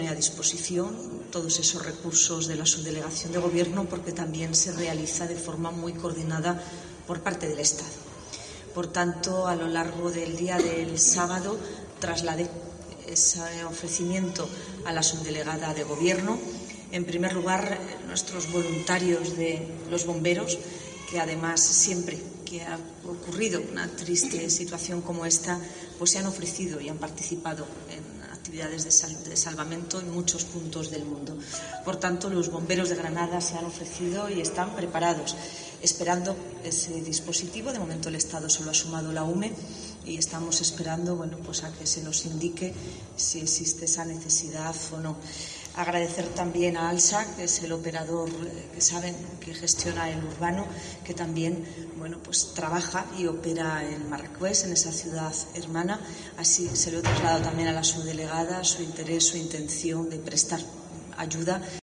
Marifrán Carazo, alcaldesa de Granada
A preguntas de los periodistas este lunes, la alcaldesa ha explicado que el Ayuntamiento está "a la espera del Estado" en este asunto, tras el traslado de este ofrecimiento que le hacía el mismo sábado a la subdelegada del Gobierno en Granada, Inmaculada López Calahorro.